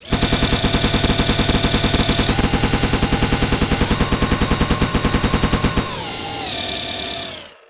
Converted and made the Gatling Cannon Sound from Generals to be usable as a replacement/alternative to the existing one.
DakkaDakkaDakka *Preview* DakkaDakkaDakka
Whenever I try to convert the audio file to the same bitrate as the ones in the audio bags it ends up having a short pause.
full_burst_preview_250.wav